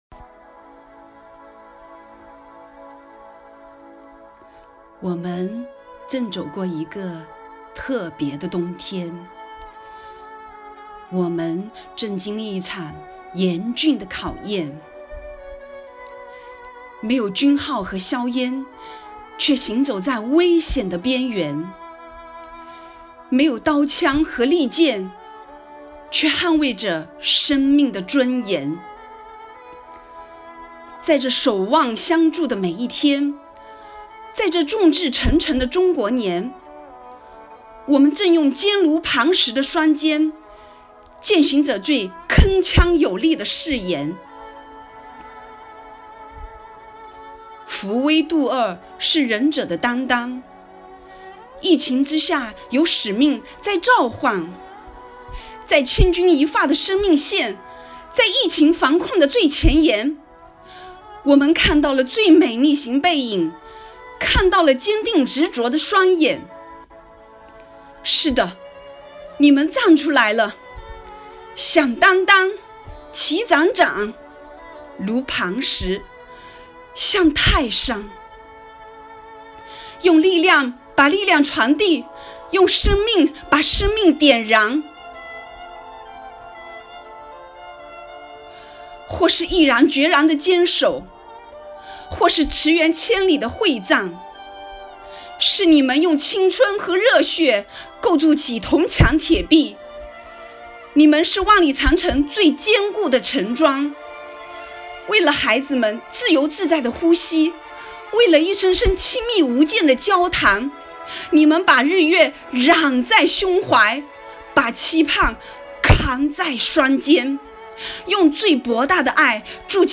为赞美“逆行英雄”甘于奉献、大爱无疆的崇高精神，女教师们精心创作或挑选朗诵诗歌作品，用饱含深情的声音歌颂和致敬奋斗在一线的抗“疫”英雄们，讴歌了中华民族的伟大和坚韧，表达了对祖国和武汉的美好祝福，热切盼望疫情过后的春暖花开。
附件：一、抗“疫”事迹诗朗诵选登